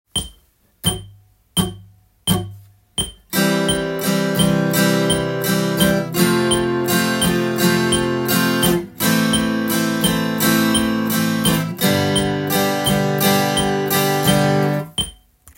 譜面通り弾いてみました
また②では、裏拍の中に少しだけ表の拍を８分音符で